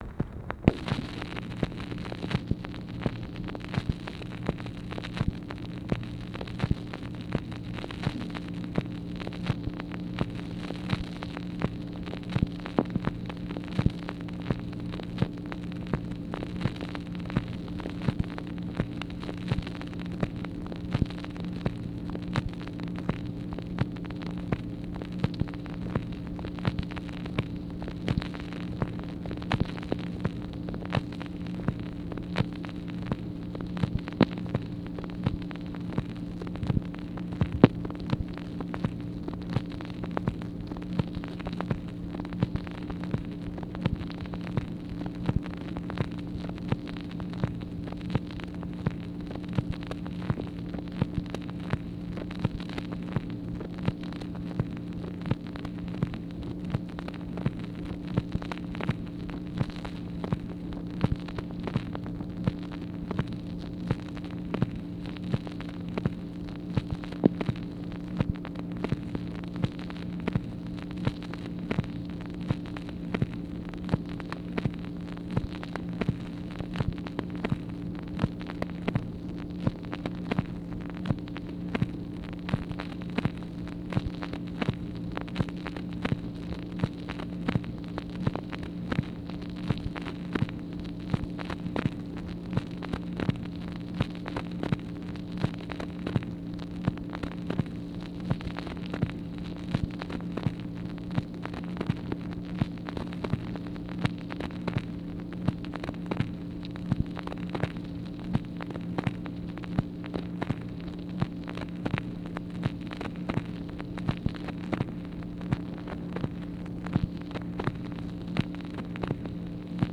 MACHINE NOISE, April 28, 1964
Secret White House Tapes | Lyndon B. Johnson Presidency